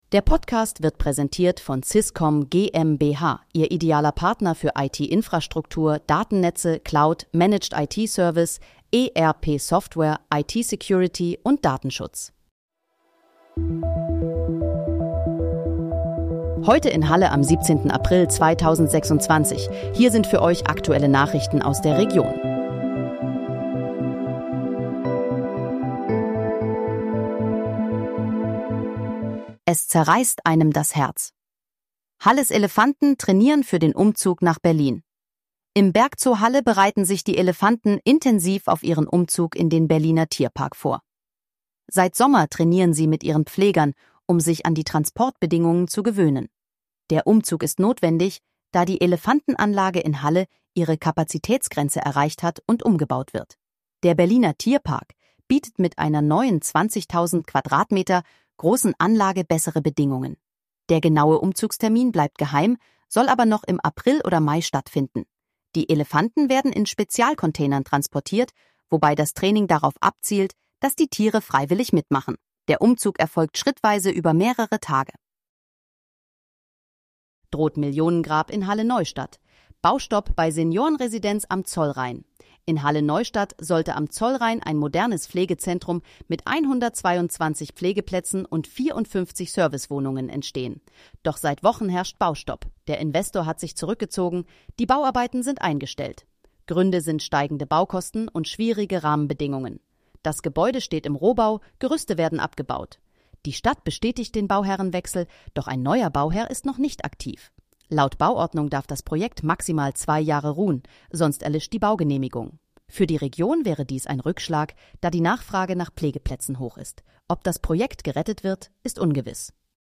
Heute in, Halle: Aktuelle Nachrichten vom 17.04.2026, erstellt mit KI-Unterstützung